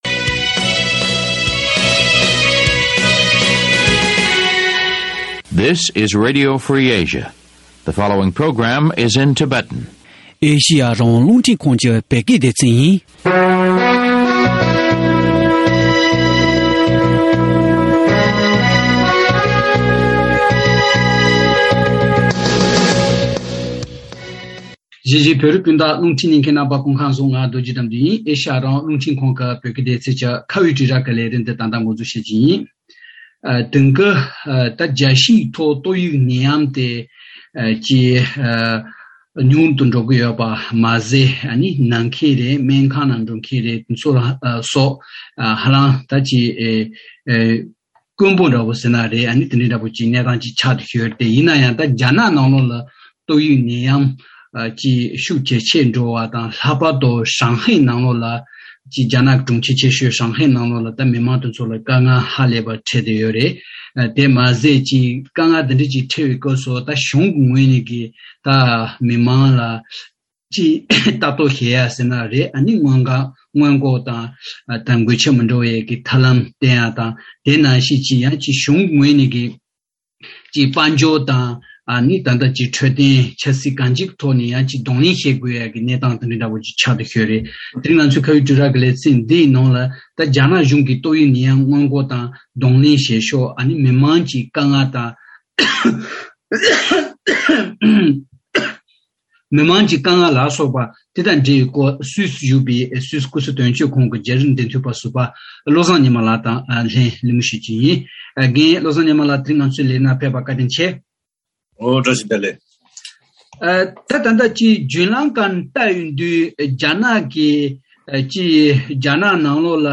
རྒྱ་ནག་གི་ཏང་གི་ཚོགས་ཆེན་༢༠པ་འཚོགས་རྒྱུའི་ཁོར་ཡུག་དང་ཆ་རྐྱེན་ཡག་པོ་ཞིག་བསྐྲུན་ཆེད་ནད་ཡམས་སྔོན་འགོག་དེ་གནད་འགག་ཇི་ཙམ་ཡོད་མེད་ཐད་གླེང་མོལ།